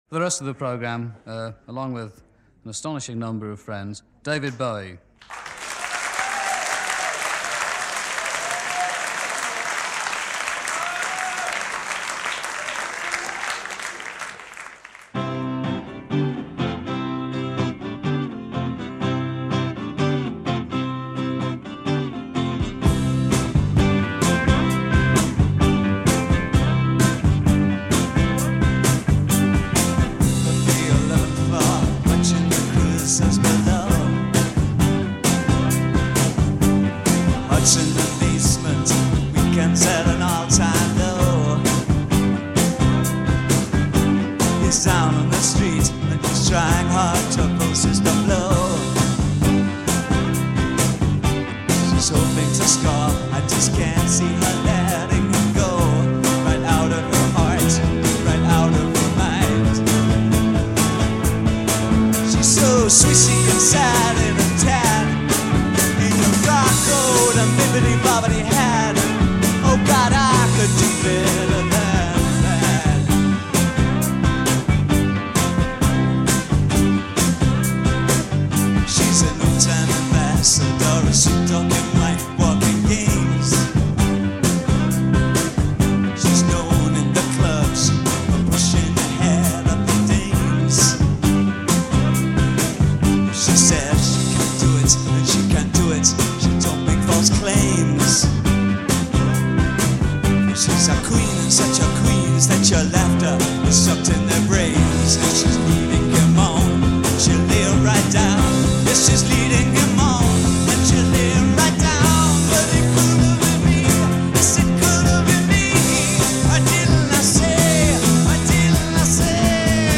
Recorded at The Paris Theatre, June 20, 1971